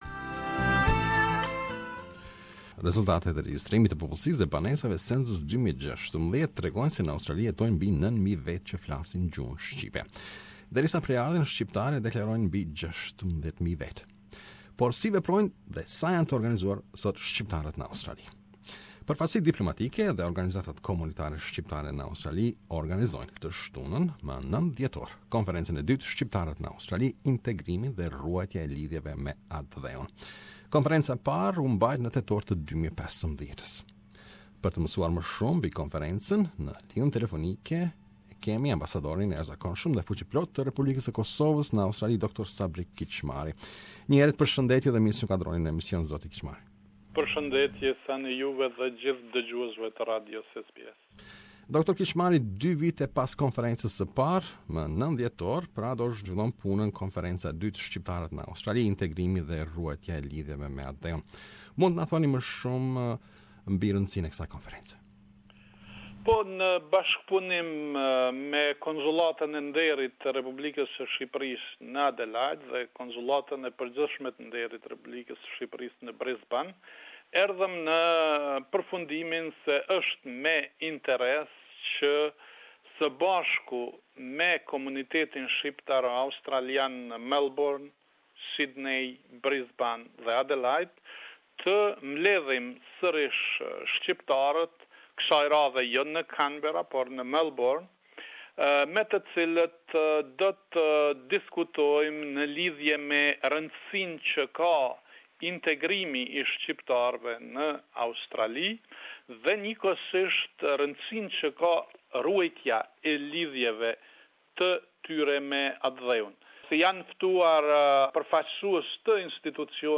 To learn more about the conference planed for 9 th December, we interviewed Ambassador of the Republic of Kosovo in Australia, Dr. Sabri Kicmari.